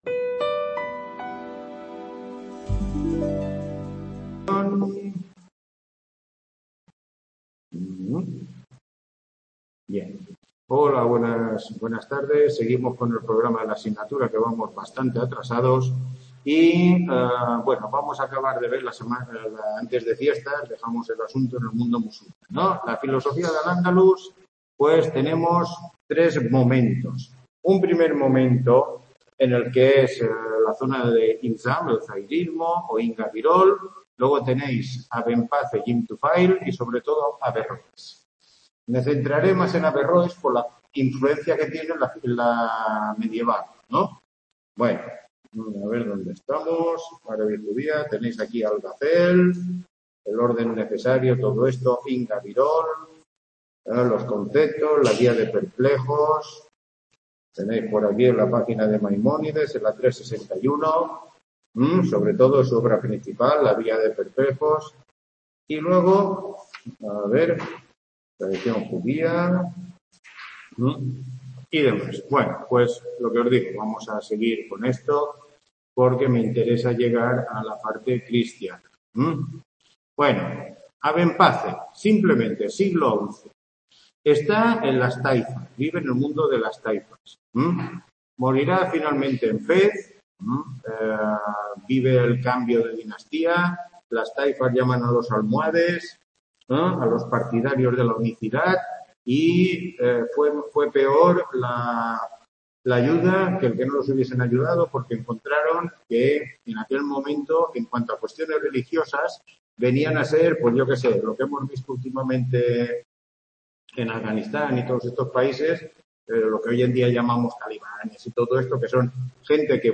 Tutoría 11